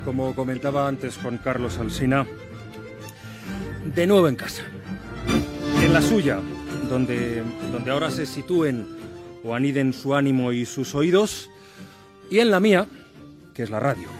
Inici de la segona part del programa en el seu primer dia d'emissió.
Info-entreteniment
FM